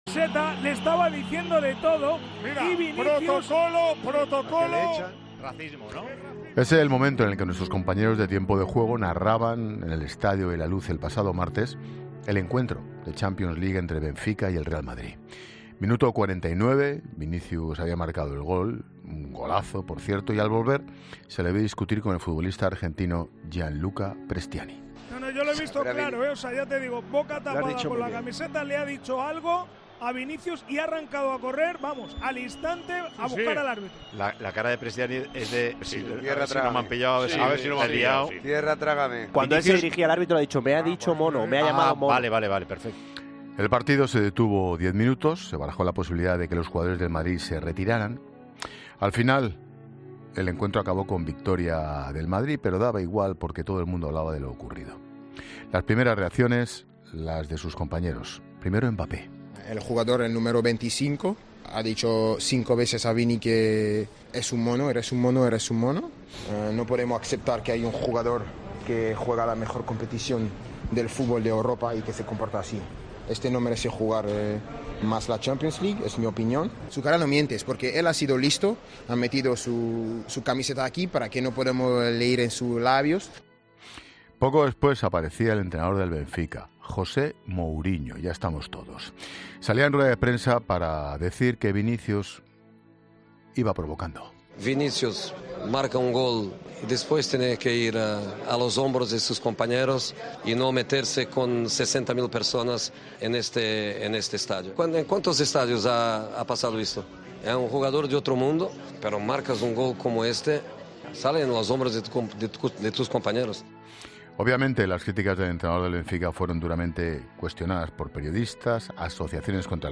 El periodista deportivo analiza en 'La Linterna' la polémica entre el jugador del Real Madrid y Prestianni y advierte de las consecuencias de una...